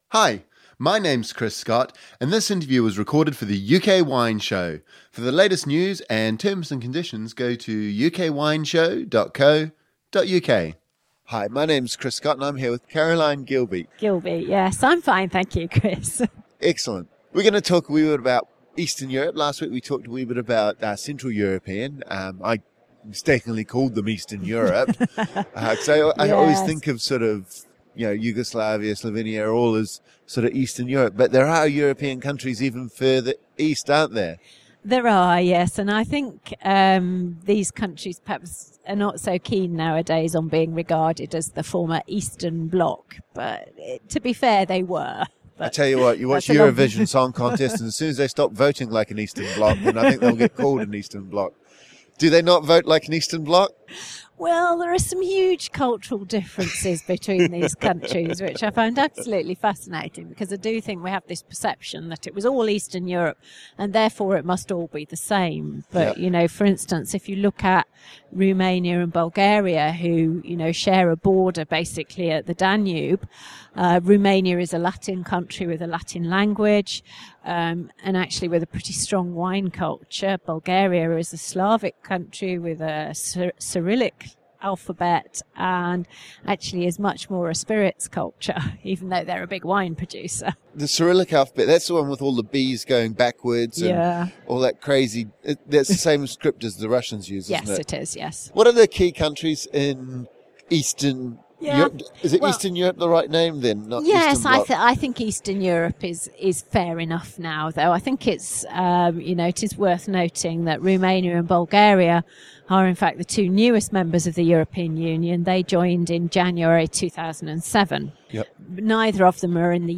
In this second interview